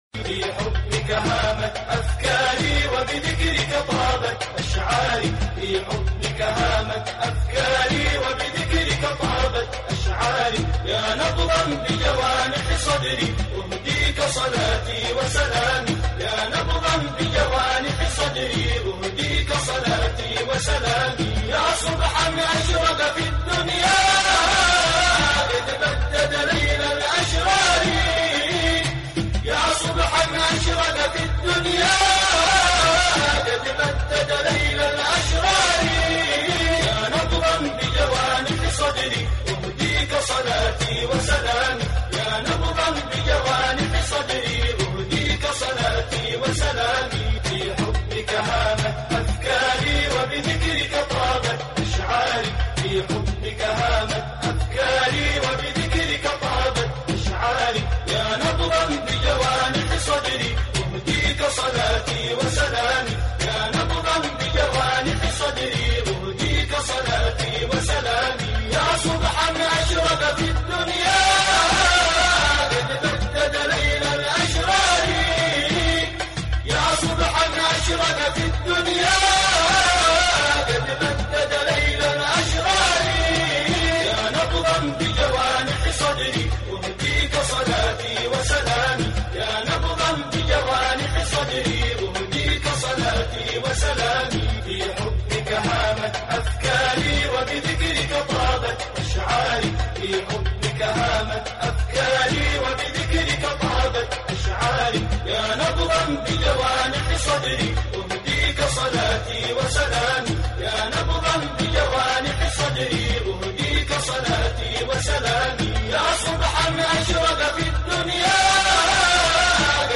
اناشيد يمنية